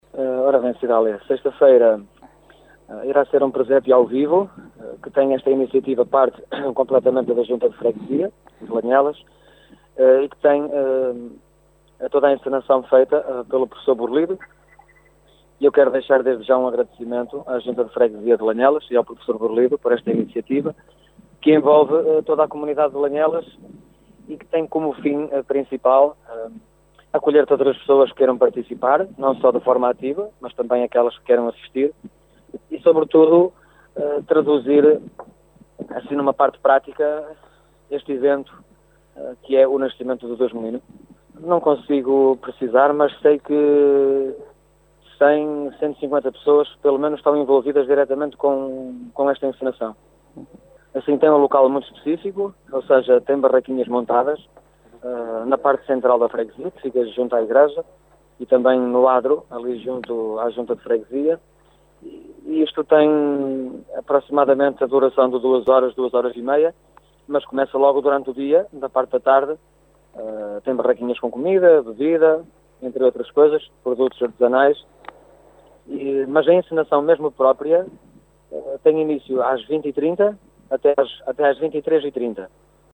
Em entrevista à Rádio Caminha